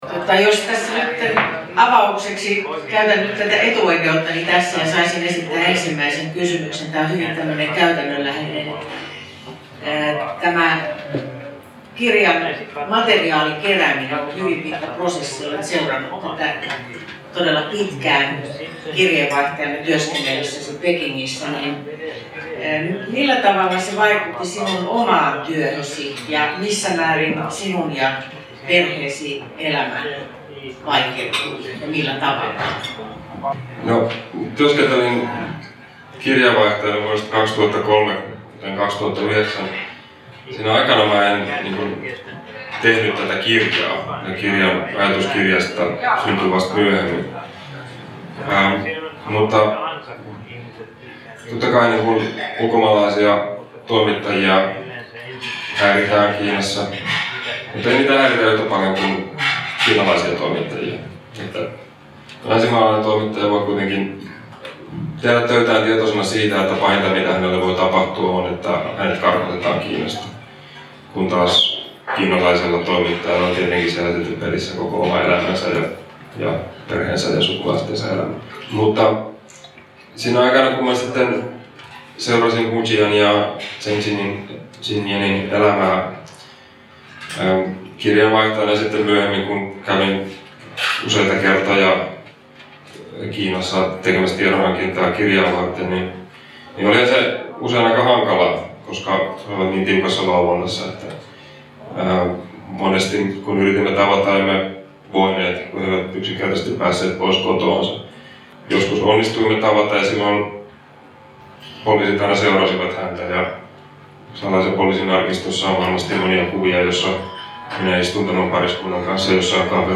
Täysi salillinen yleisöä kerääntyi 5.4.2024 Fiskarsin ravintola Kuparipajassa järjestettyyn Ajattelu on ilmaista -tapahtumaan.